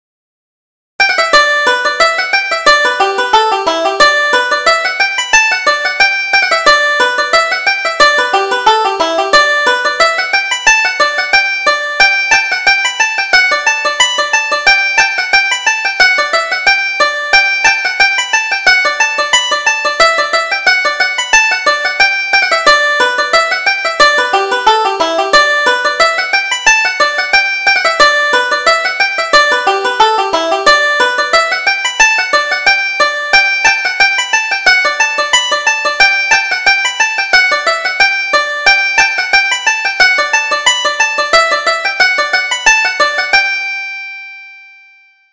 Reel: Kiss Your Partner